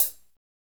Index of /90_sSampleCDs/Northstar - Drumscapes Roland/DRM_AC Lite Jazz/HAT_A_C Hats x